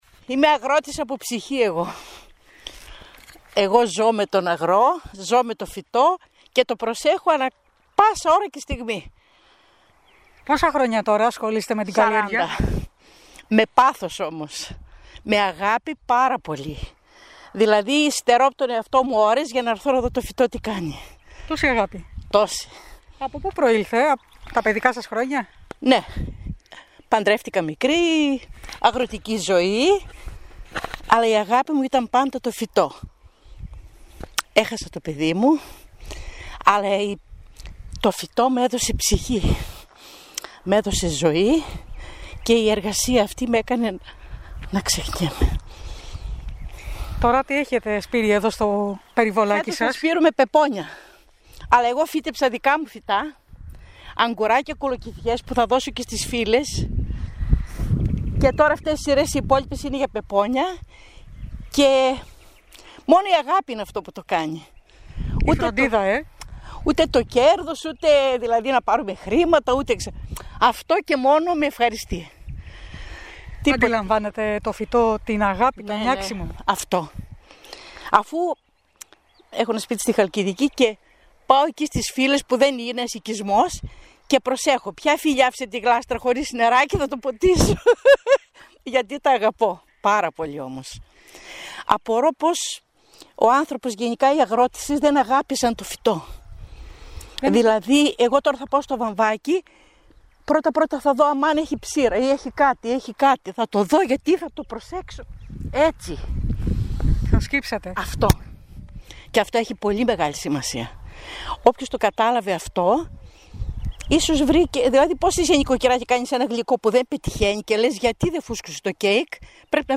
«Στερώ από τον εαυτό μου ώρες για να έρθω και να δω τι κάνουν τα φυτά» μας είπε και διακόπτοντας το καθάρισμα στάθηκε να μιλήσει μαζί μας.